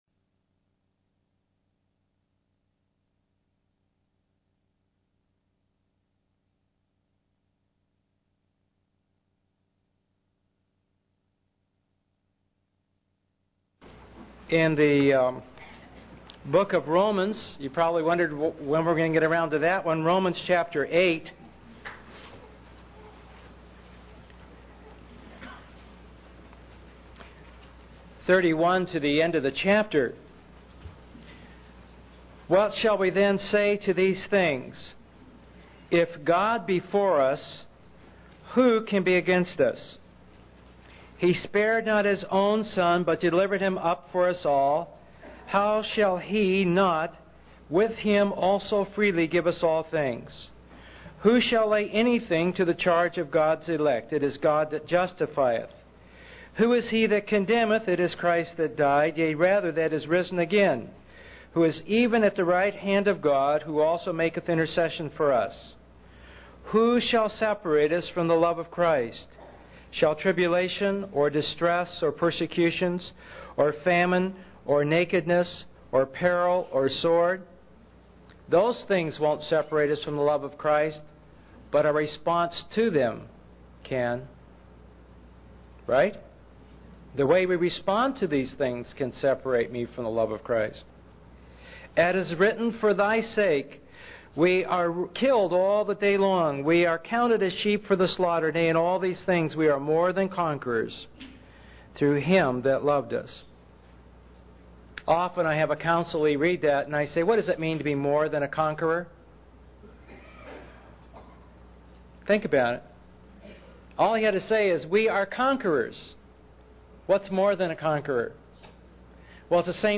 In this sermon, the speaker discusses the importance of not treating special days like unbelievers do. They emphasize the need to read and understand the Bible to gain insight into spiritual warfare and how to help those who are struggling. The speaker references Ephesians chapter four, specifically focusing on the dangers of anger and giving Satan a foothold in one's life.